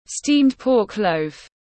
Giò lụa tiếng anh gọi là steamed pork loaf, phiên âm tiếng anh đọc là /stiːmd pɔːk ləʊf/
Steamed pork loaf /stiːmd pɔːk ləʊf/